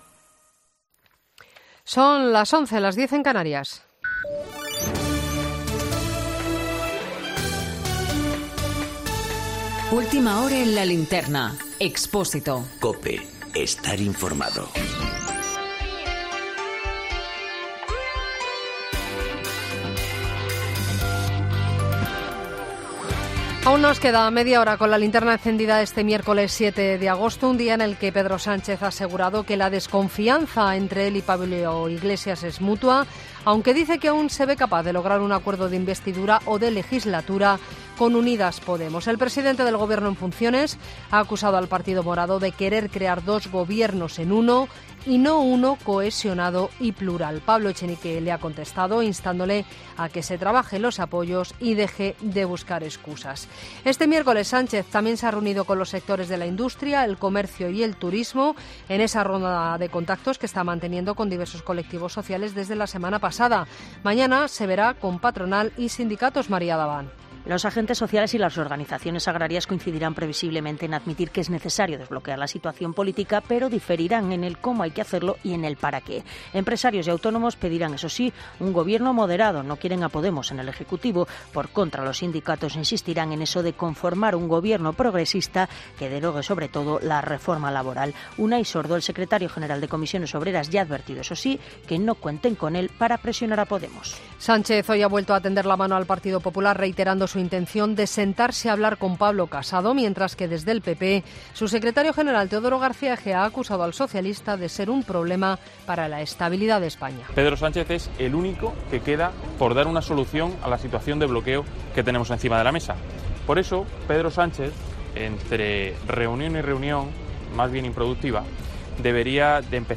Boletín de noticias de COPE del 7 de agosto de 2019 a las 23.00 horas